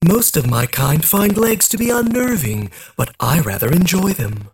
107 KB Viscous voice line (unfiltered) - Most of my kind find legs to be unnerving, but I rather enjoy them. 1